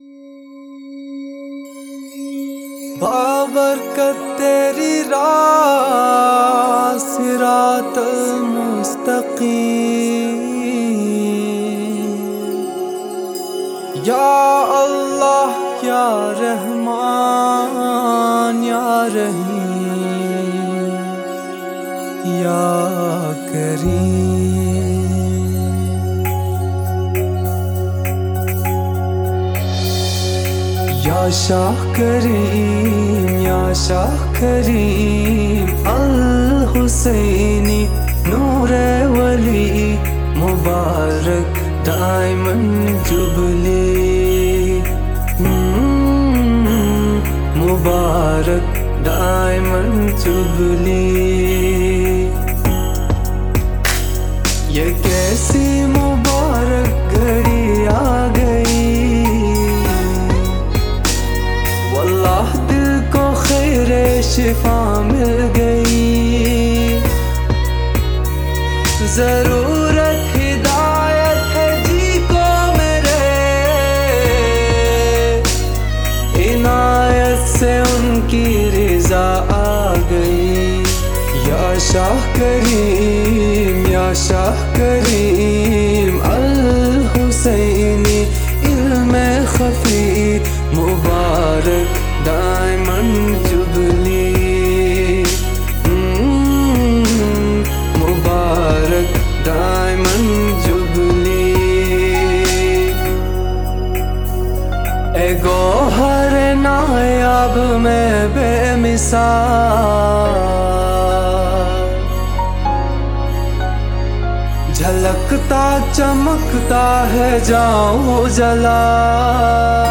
Listen to this wonderful song here, in his very powerful voice.